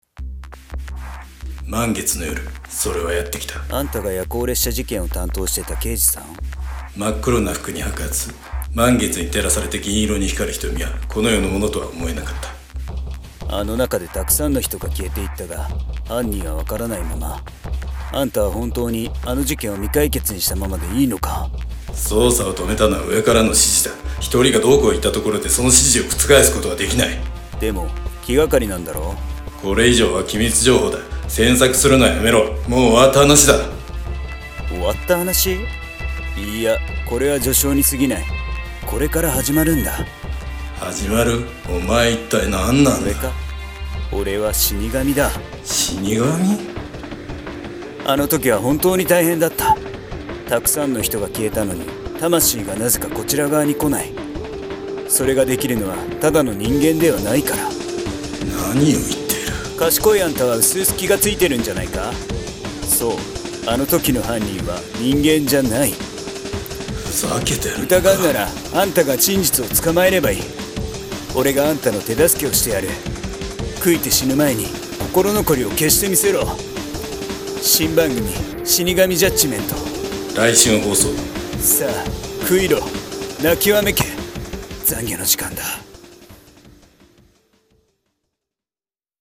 【声劇】死神ジャッジメント！【2人声劇】コラボ済